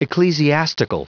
Prononciation du mot ecclesiastical en anglais (fichier audio)
Prononciation du mot : ecclesiastical